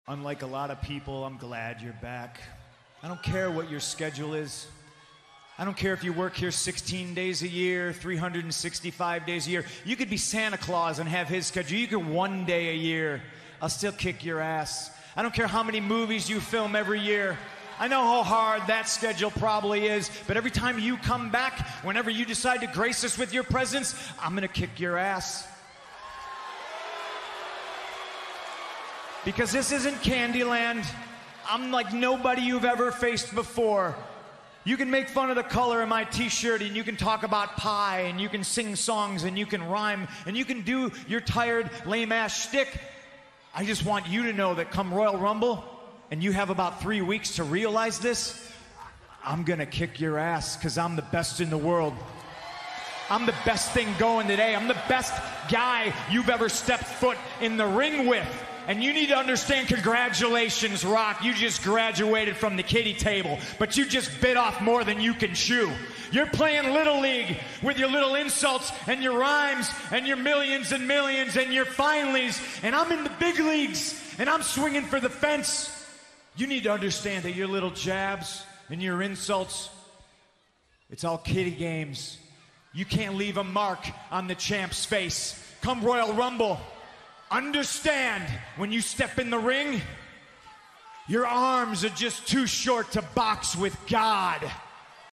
Cm Punk Promo With The Rock On RAW 2013